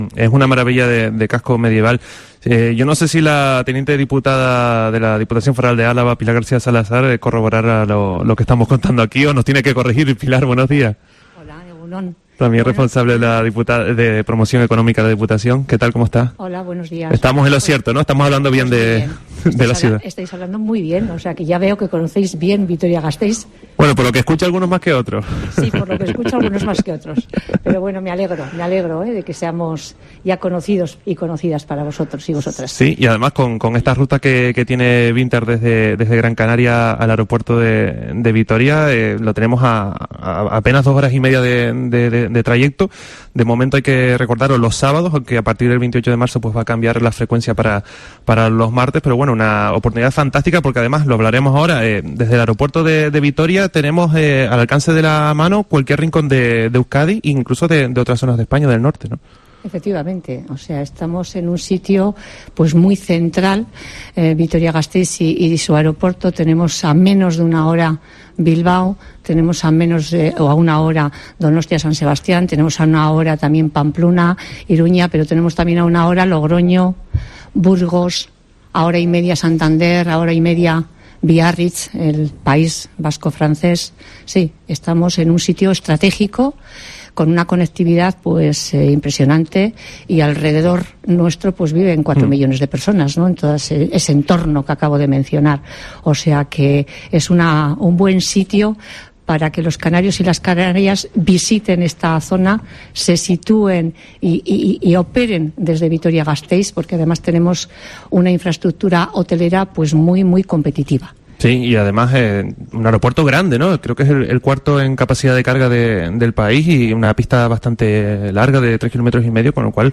Entrevista a Pilar García Salazar, teniente diputada y diputada de la diputación de Álava